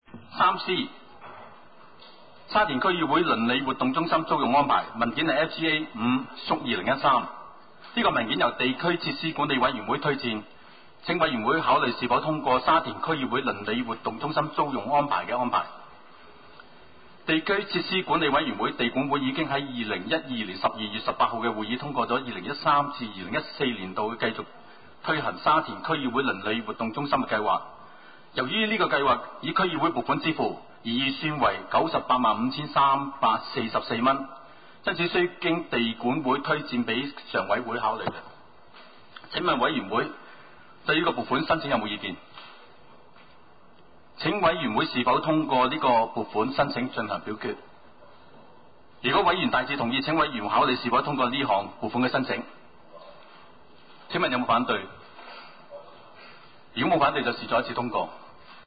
沙田区议会 - 委员会会议的录音记录
委员会会议的录音记录